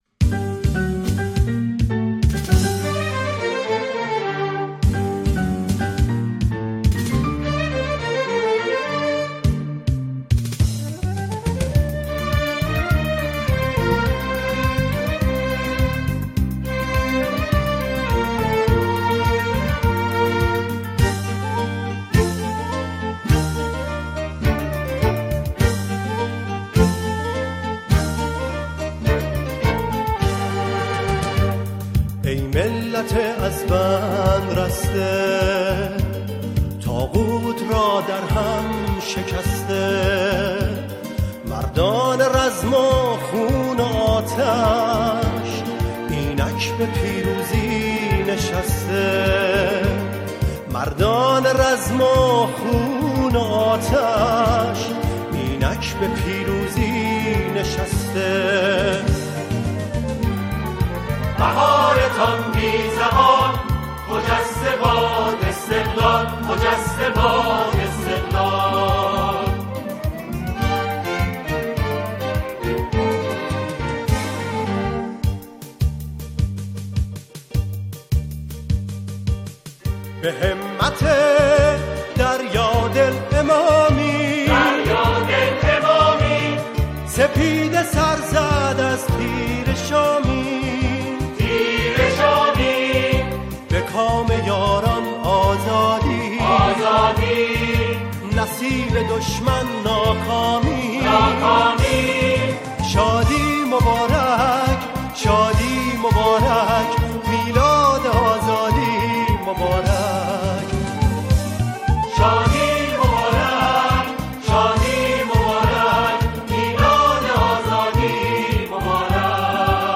گروهی از همخوانان اجرا می‌کنند